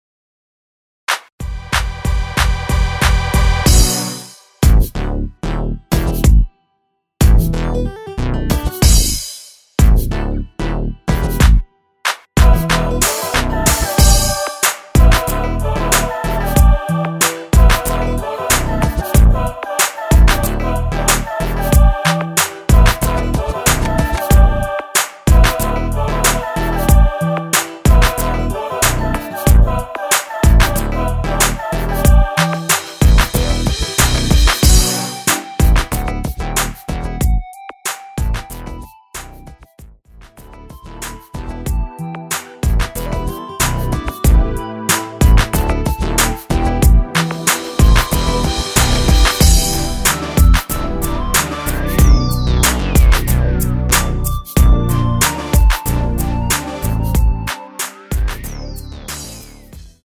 원키 멜로디 포함된 MR입니다.
Em
앞부분30초, 뒷부분30초씩 편집해서 올려 드리고 있습니다.
중간에 음이 끈어지고 다시 나오는 이유는